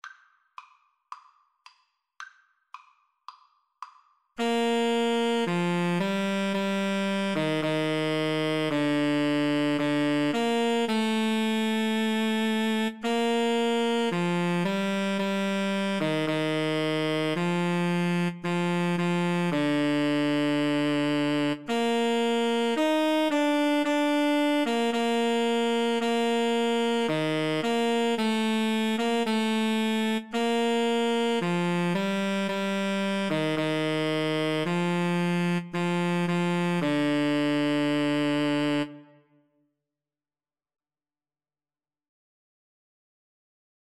Tenor Sax Duet  (View more Easy Tenor Sax Duet Music)
Classical (View more Classical Tenor Sax Duet Music)